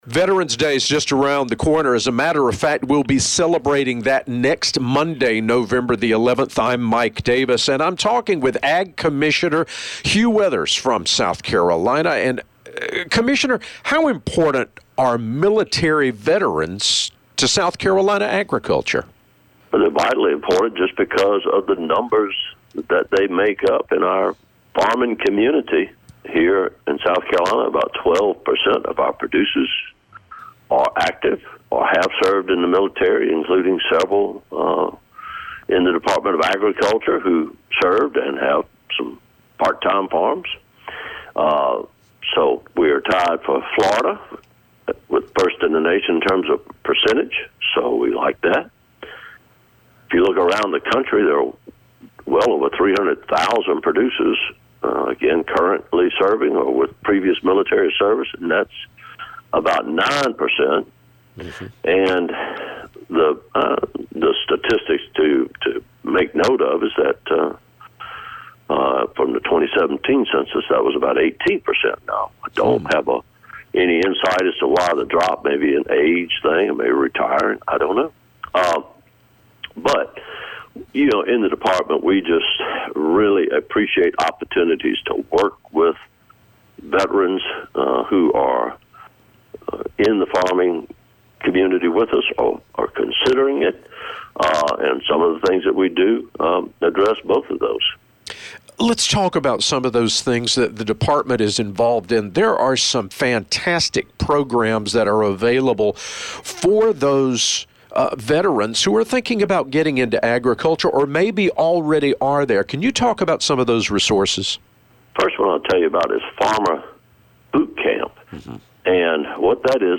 Every week Commissioner Hugh Weathers talks about what’s going on with agriculture in our state with The Southern Farm Network.